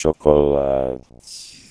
Para os parâmetros perfilTempoperfilF0 escolhidos na ilustração acima, o resultado soa como um ‘agravamento’ da voz original (cujo F0 original se encontra no entorno de 120 Hz – voz masculina média) e numa distorção no ritmo da fala que pode ser escutada com a execução do código, ou no ‘play’ abaixo: